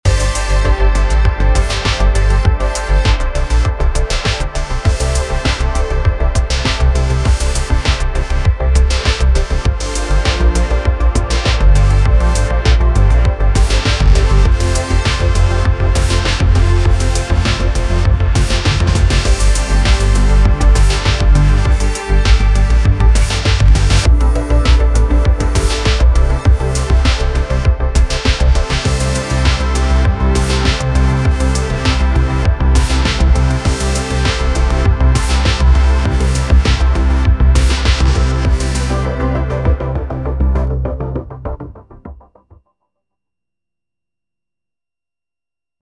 I tried Code: JRPG orchestral battle music, piano, energetic, trumpets, drums, triangle and got this: